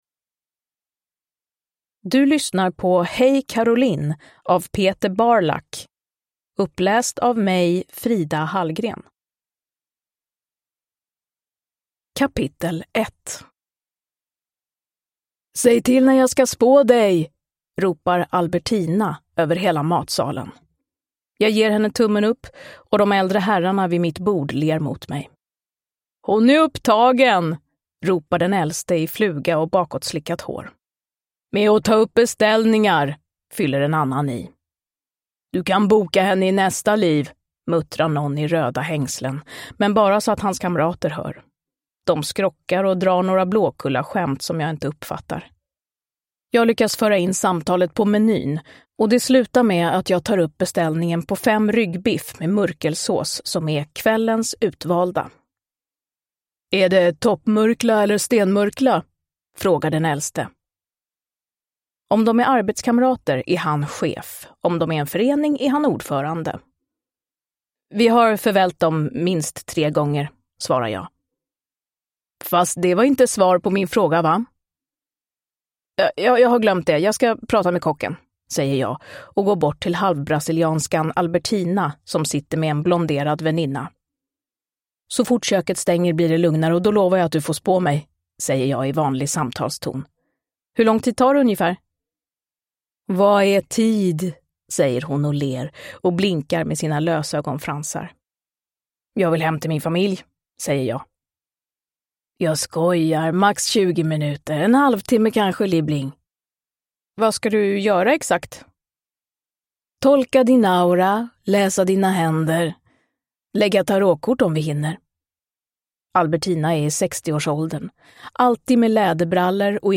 Uppläsare: Frida Hallgren
Ljudbok